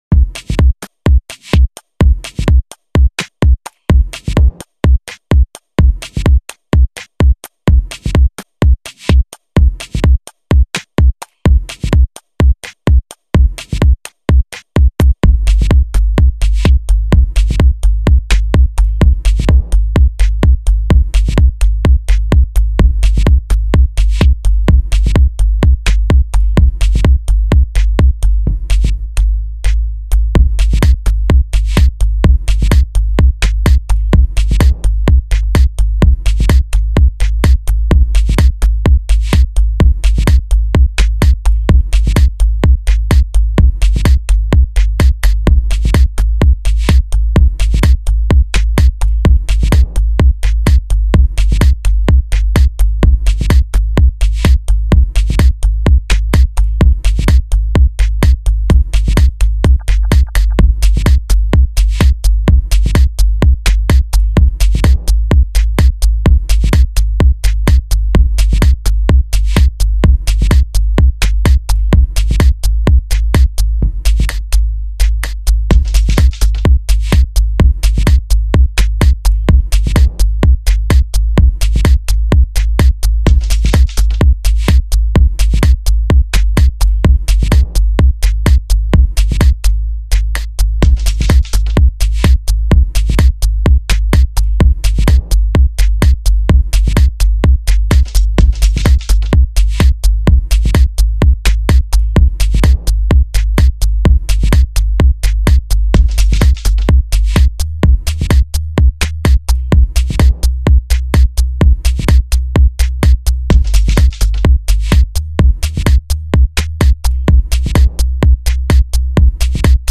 Tech House